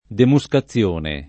[ demu S ka ZZL1 ne ]